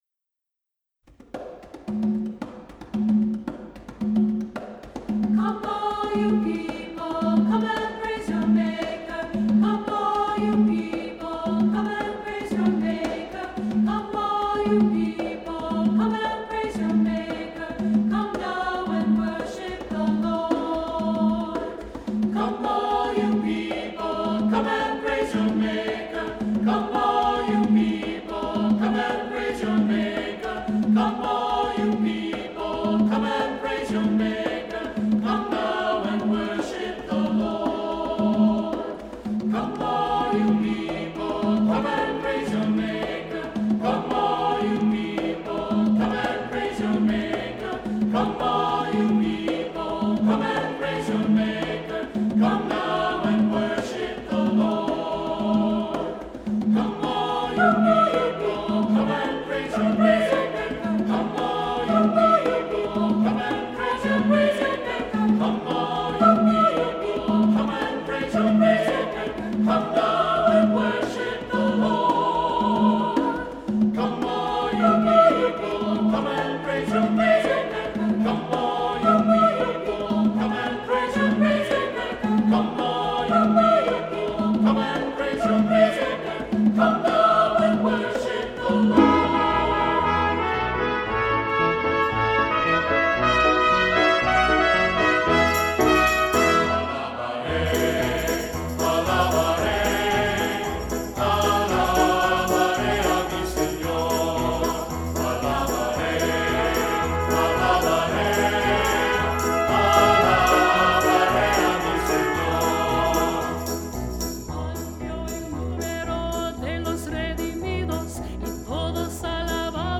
Music Category:      Christian
Cantor or Solo Percussion part is for Conga